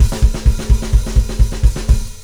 Black Metal Drum Rudiments
Beat 1 - The Thrash Beat
thrash3.wav